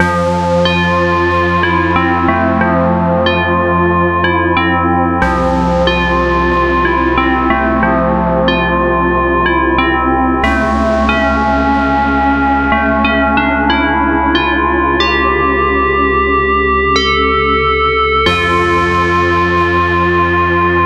描述：钢琴与木槌和合成器垫。
Tag: 92 bpm Hip Hop Loops Orchestral Loops 3.53 MB wav Key : Unknown